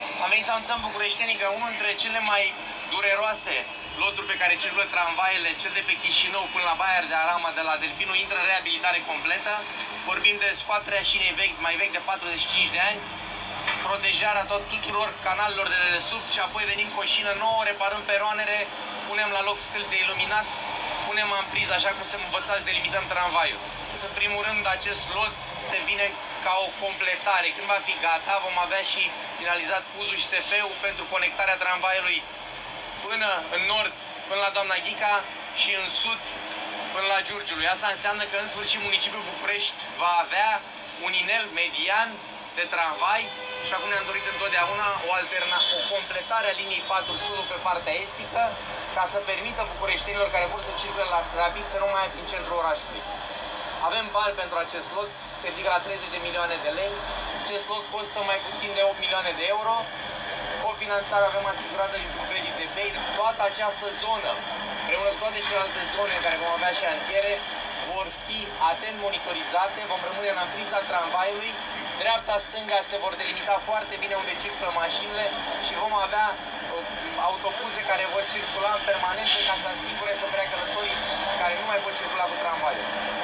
În cursul lucrărilor de modernizare a liniei de tramvai, va fi modernizată  toată  infrastructura edilitară , a mai explicat Bujduveanu.